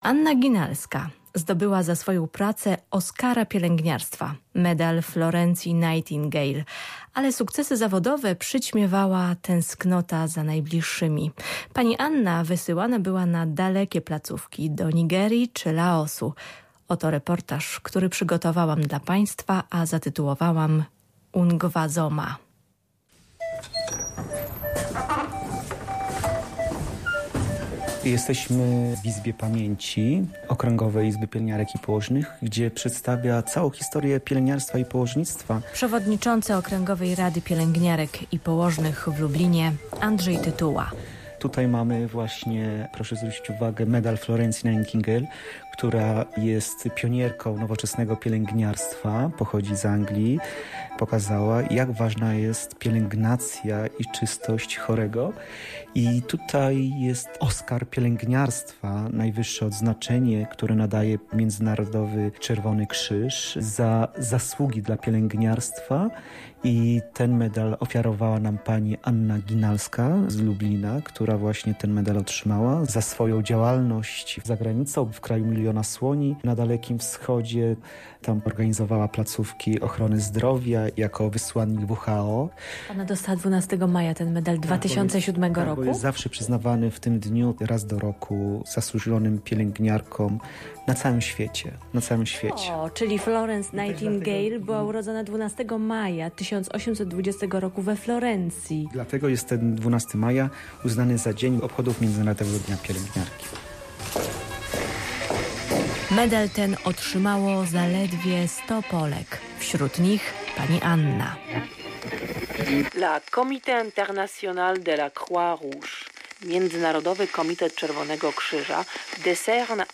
DRZEWO RODZINNE Reportaż